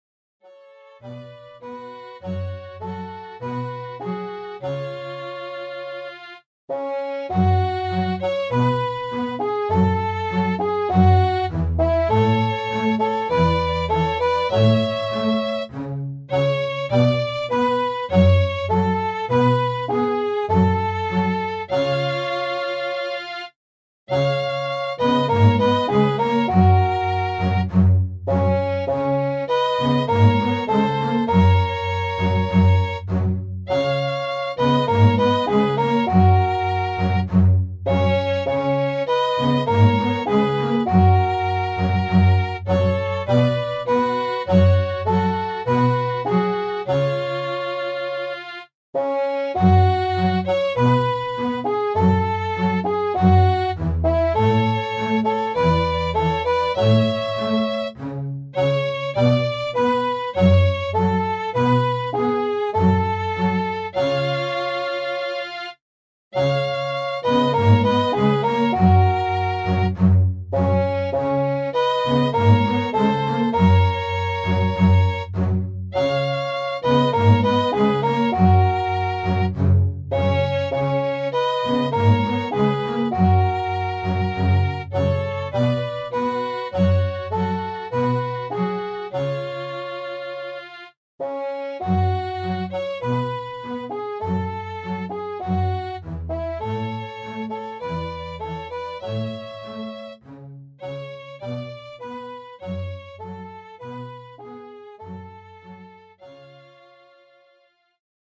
Mehrstimmige Melodien mit wundersamen Klängen